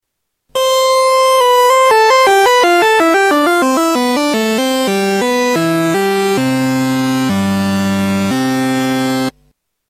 Tags: Sound Effects Orca Demos FXpansion Orca FXpansion Soft Synth